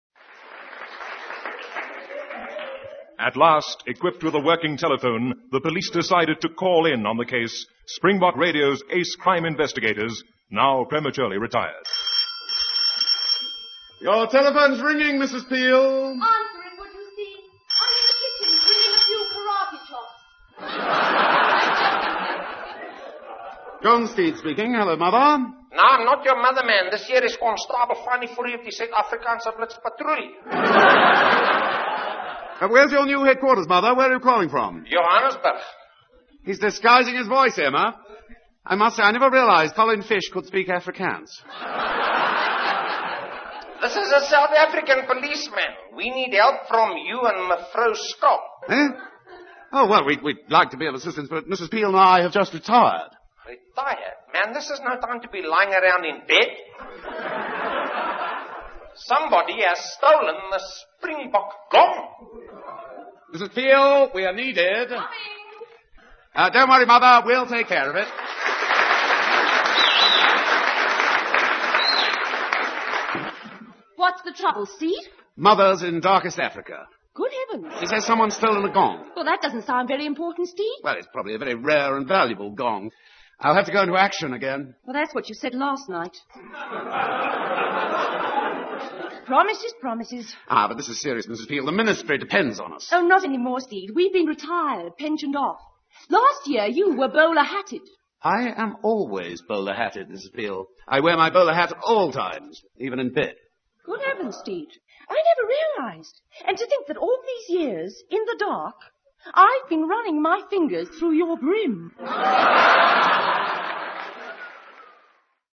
The programme was broadcast live from the SABC Variety Theatre in Broadcast House, Johannesburg on 30th April 1975.